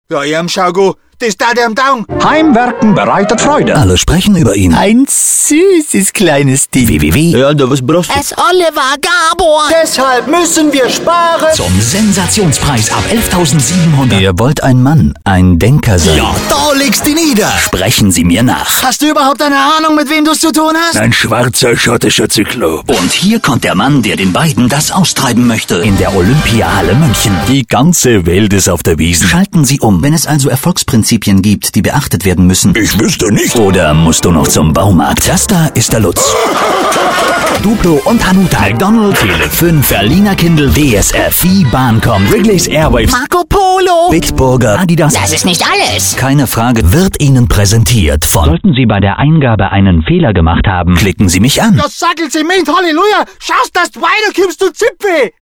deutscher Sprecher für Werbespots, Hörspiele und Hörbücher, Podcasts, E-Journals und Business Radio Special: Münchener Dialekt Bayerisch
Sprechprobe: Sonstiges (Muttersprache):
german voice over talent